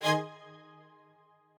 admin-leaf-alice-in-misanthrope/strings34_2_014.ogg at main